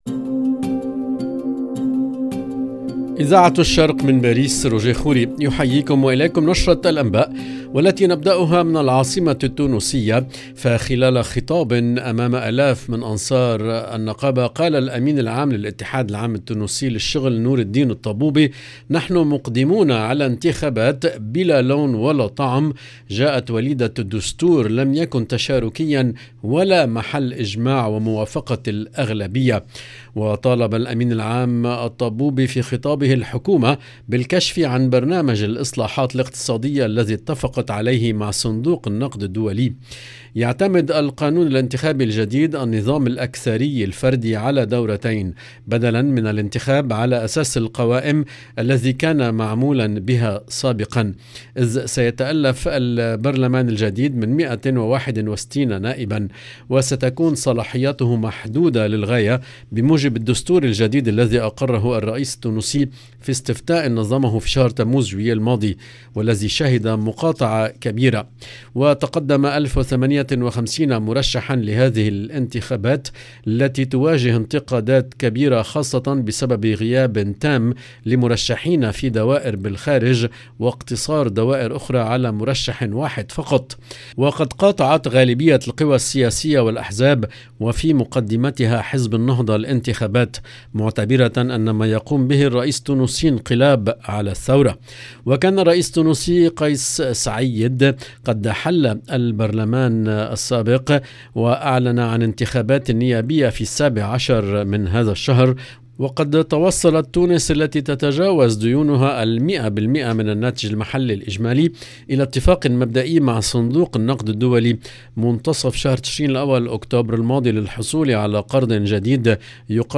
EDITION DU JOURNAL DU SOIR EN LANGUE ARABE DU 3/12/2022